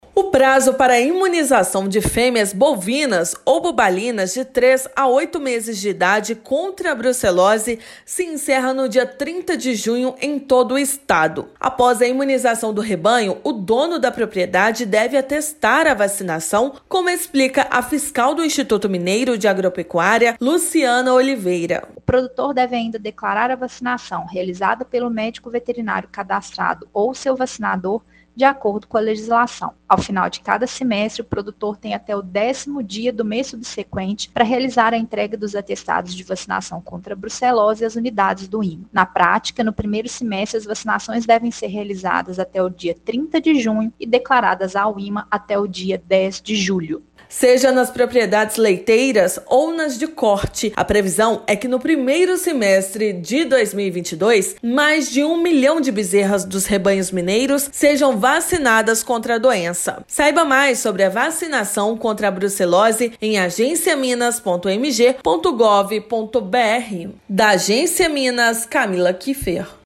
Produtores devem imunizar fêmeas bovinas e bubalinas de 3 a 8 meses de idade e comprovar o procedimento ao IMA até 10/7. Ouça matéria de rádio.